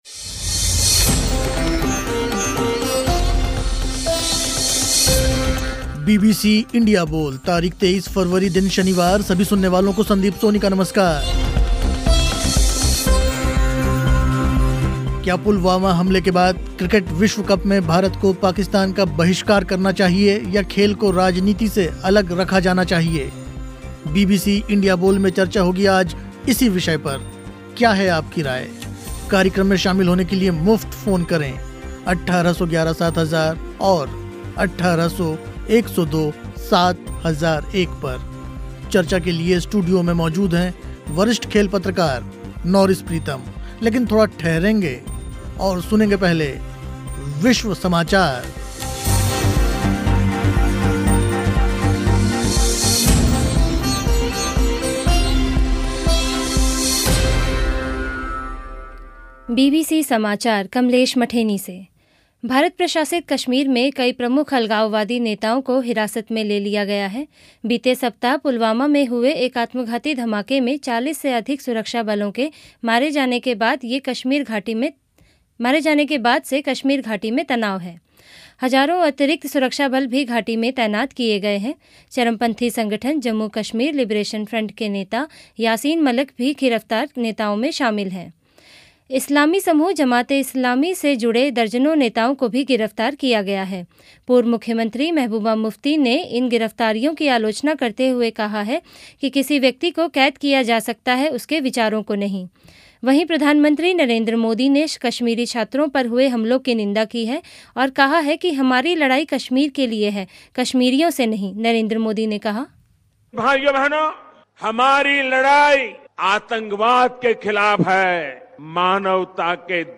बीबीसी इंडिया बोल में चर्चा हुई इसी विषय पर. स्टूडियो में मौजूद थे वरिष्ठ खेल पत्रकार